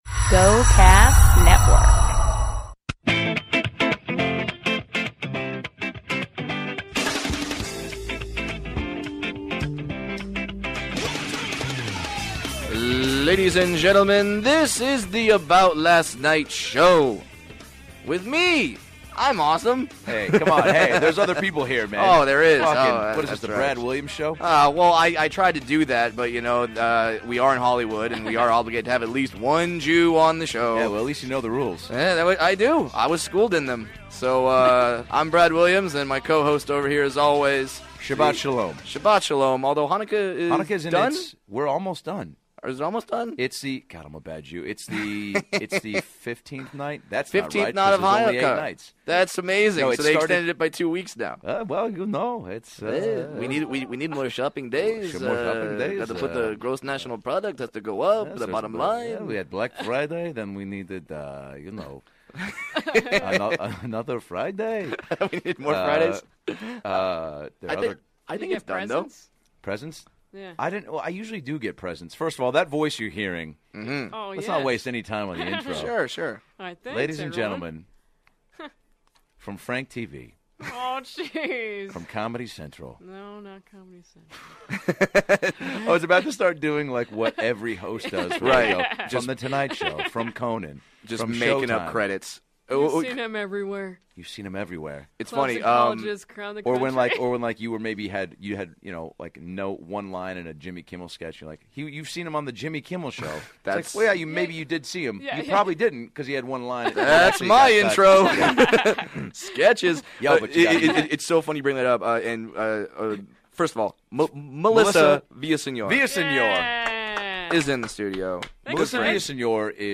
stops by to share some of her incredible impressions